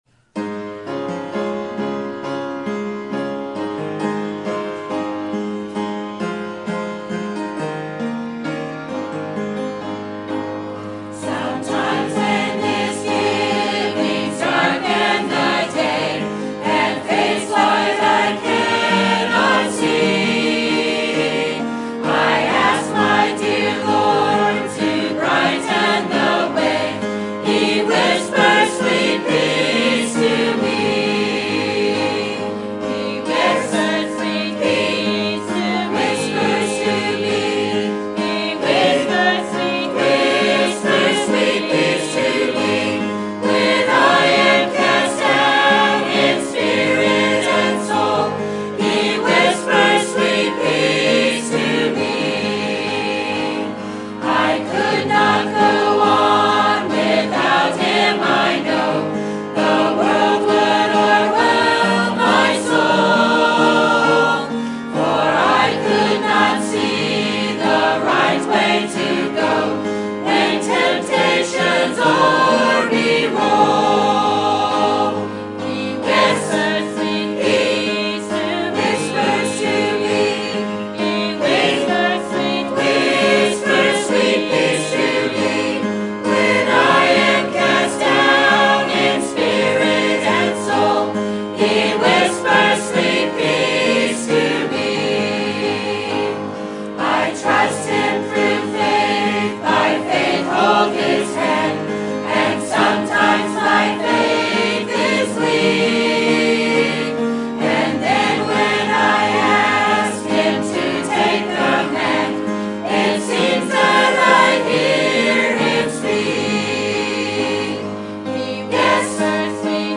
Sermon Topic: Fullness of Christ Sermon Type: Series Sermon Audio: Sermon download: Download (28.26 MB) Sermon Tags: Colossians Fullness Christ Transform